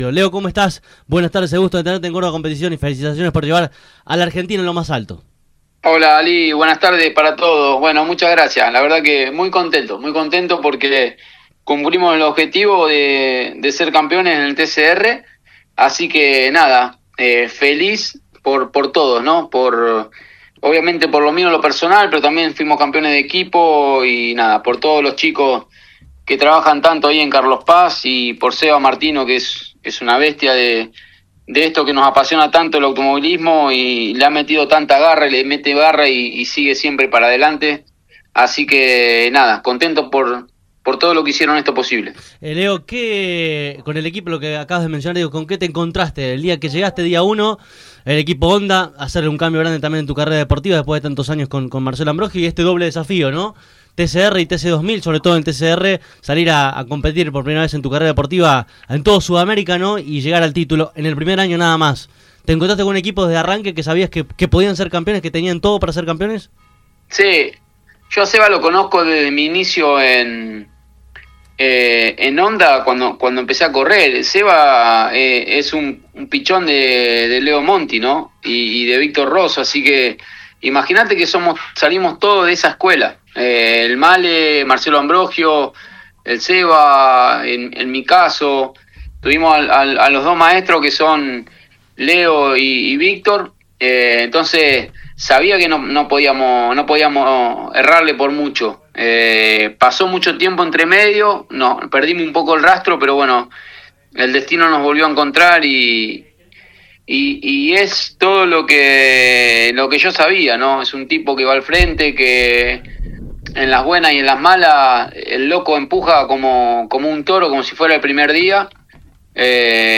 En una charla conjunta, ambos protagonistas repasaron la temporada, la compleja y definitoria carrera en Cuiabá del pasado fin de semana, la fecha que resta en Interlagos, la mirada puesta en el Stock Car para 2026 y mucho más.